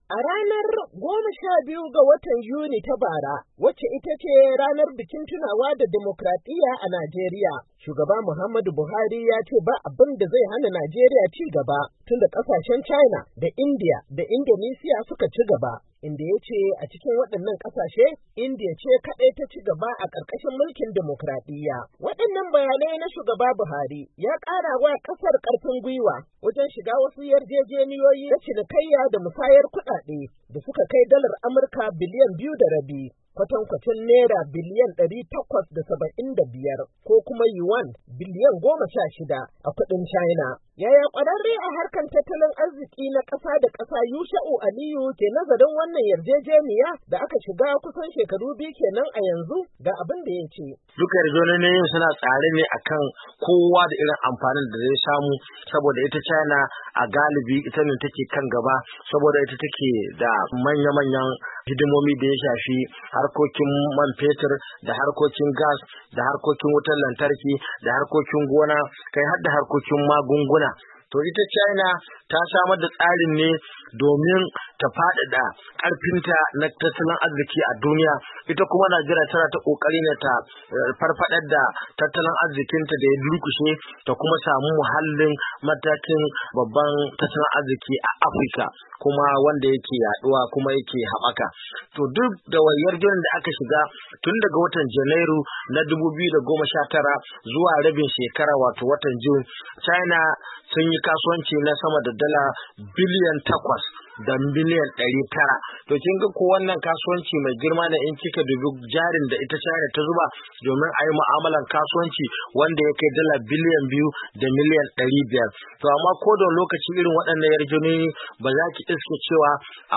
Ga wakiliyar Sashin Hausa